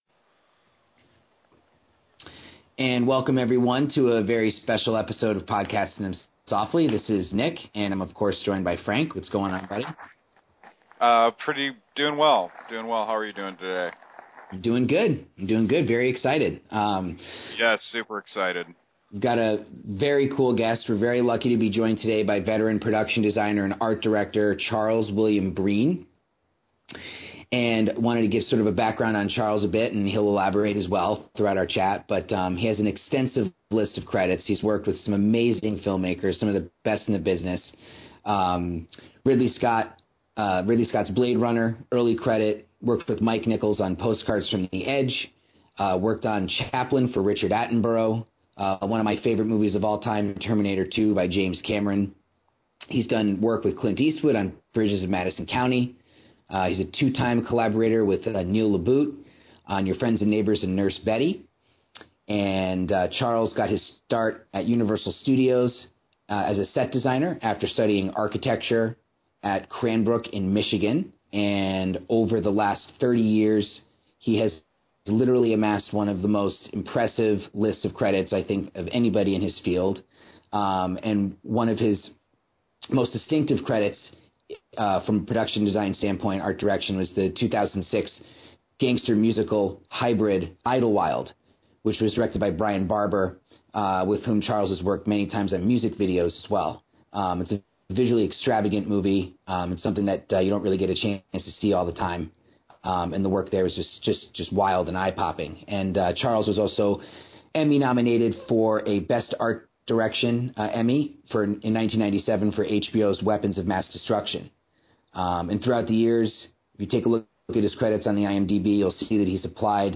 We hope you enjoy this informative and passionate discussion!